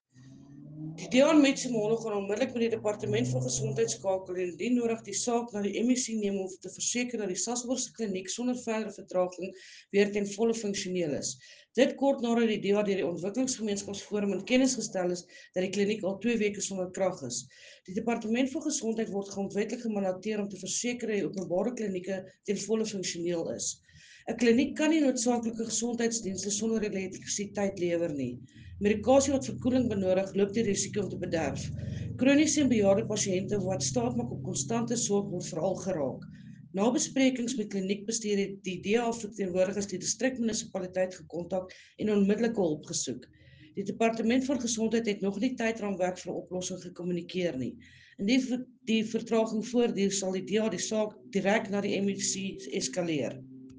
Afrikaans soundbite by Cllr Linda Day